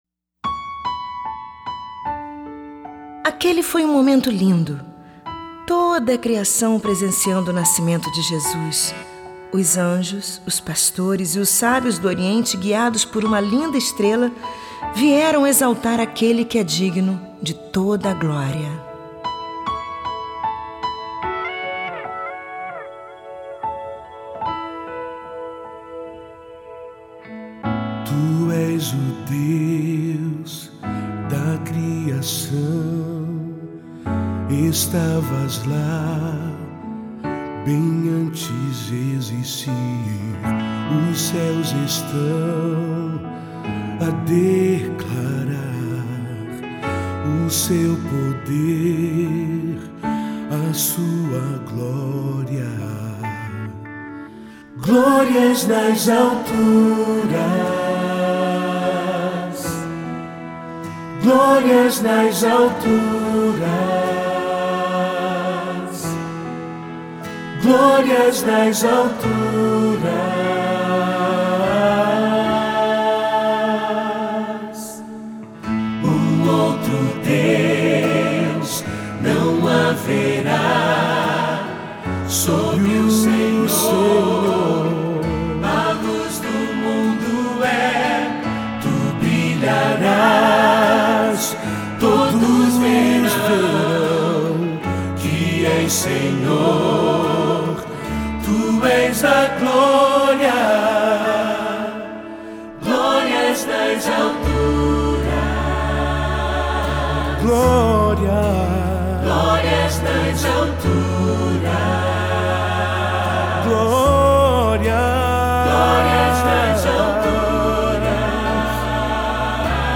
sua próxima cantata de Natal.